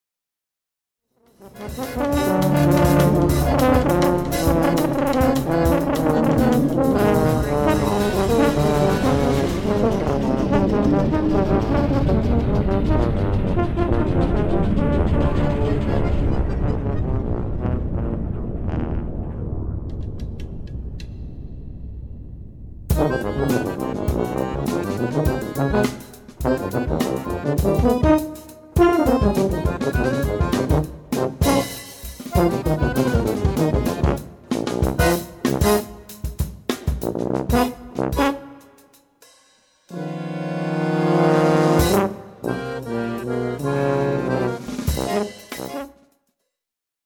Trombone | Tuba | Bass Trombone | Euphonium | Sacqueboute